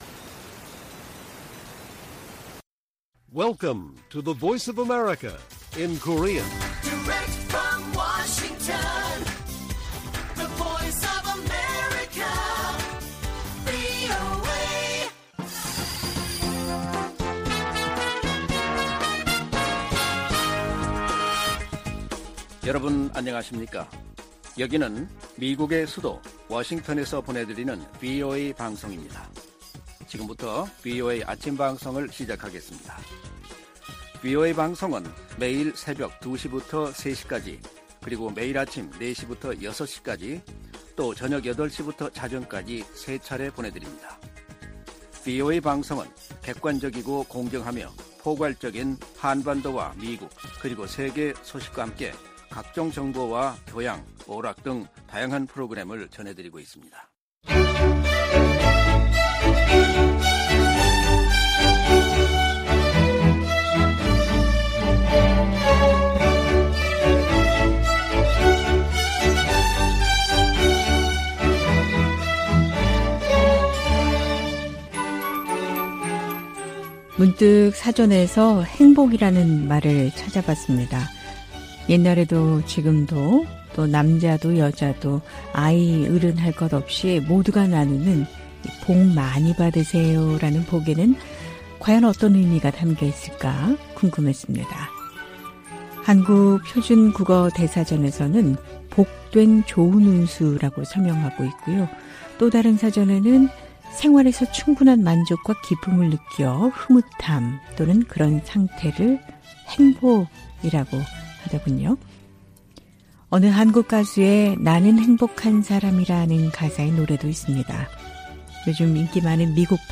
VOA 한국어 방송의 월요일 오전 프로그램 1부입니다. 한반도 시간 오전 4:00 부터 5:00 까지 방송됩니다.